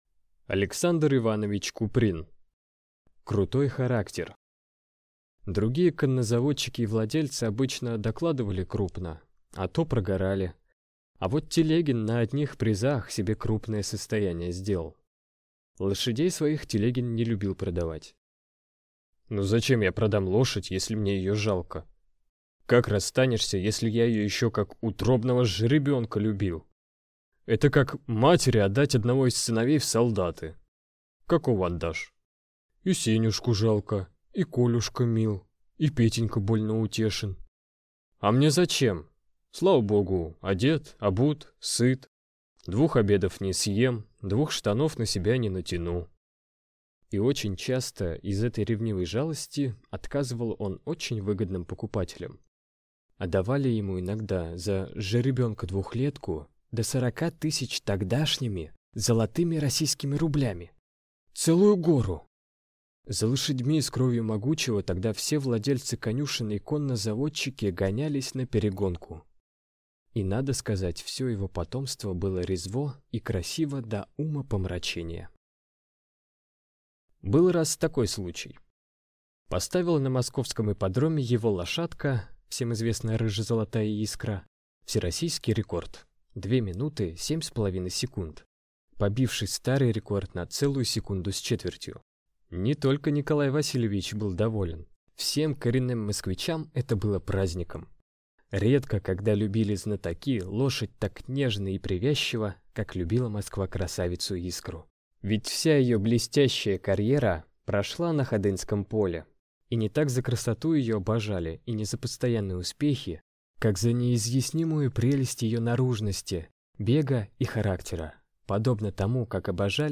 Аудиокнига Крутой характер | Библиотека аудиокниг